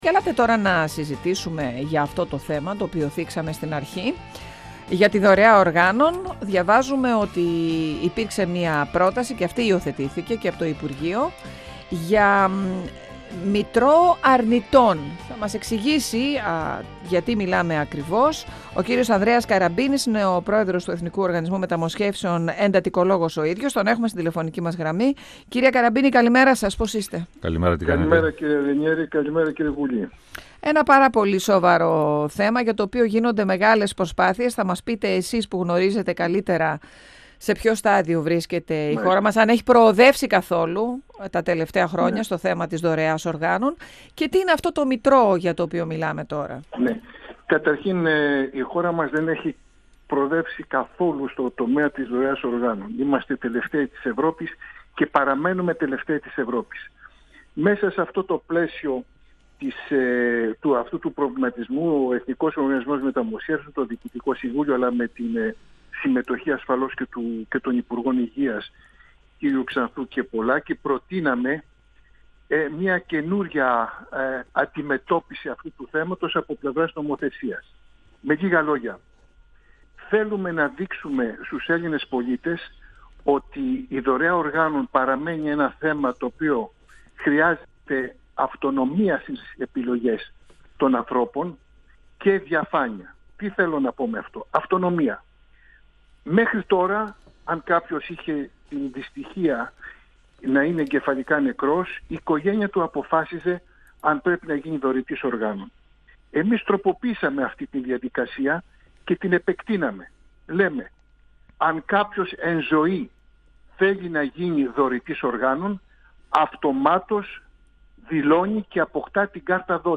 Προσπάθεια για να αυξηθεί ο αριθμός των δωρητών οργάνων καταβάλλει το υπουργείο Υγείας και ο Εθνικός Οργανισμός Μεταμοσχεύσεων. Διαπιστώθηκαν ελλείψεις και πλέον με τροπολογία θα κληθούν όλοι να συναινέσουν στη δωρεά οργάνων ή να την αρνηθούν, ανέφερε ο πρόεδρος του ΕΟΜ, Ανδρέας Καραμπίνης, μιλώντας στον 102FM του Ραδιοφωνικού Σταθμού Μακεδονίας της ΕΡΤ3.
Συνεντεύξεις